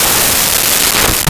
Rifle 2
Rifle_2.wav